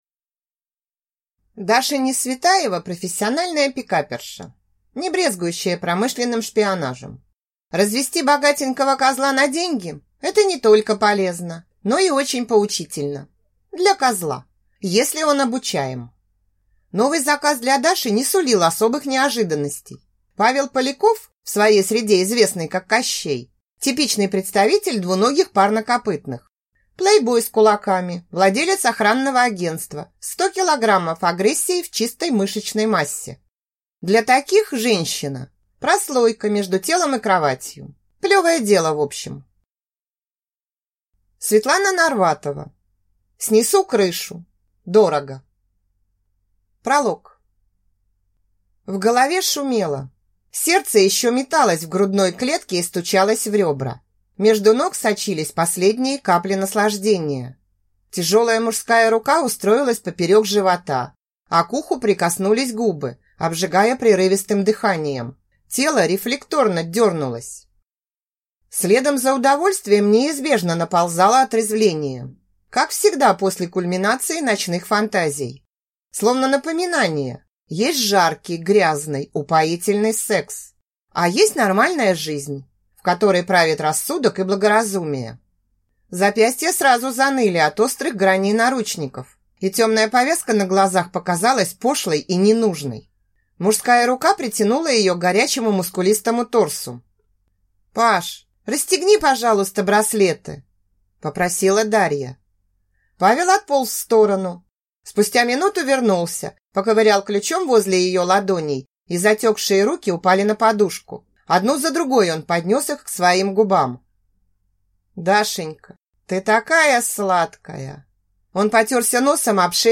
Аудиокнига Снесу крышу. Дорого | Библиотека аудиокниг